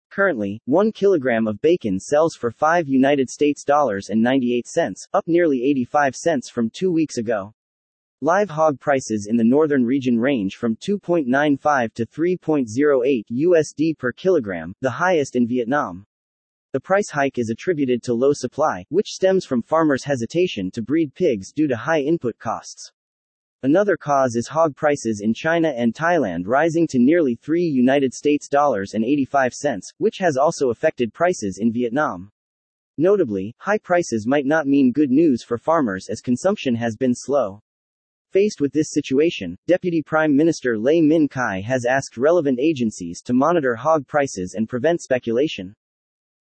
Nguồn: VTV news